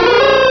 -Replaced the Gen. 1 to 3 cries with BW2 rips.
hoothoot.aif